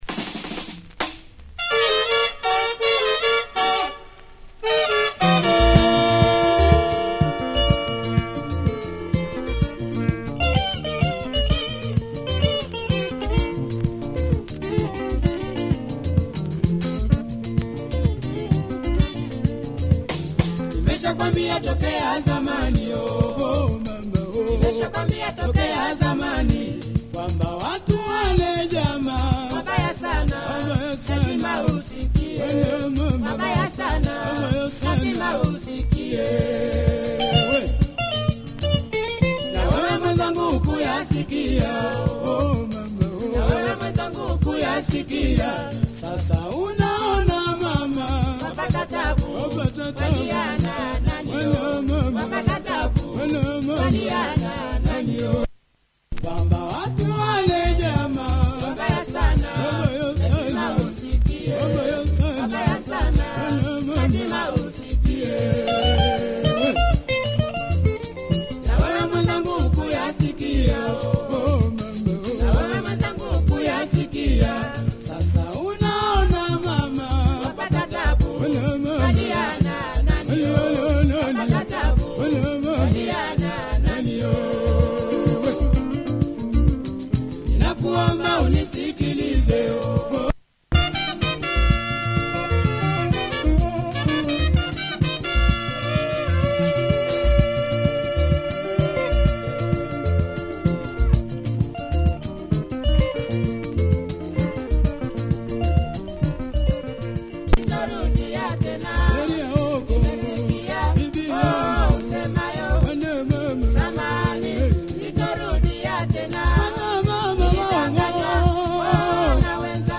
Nice track by this famous outfit, nice horns cool label!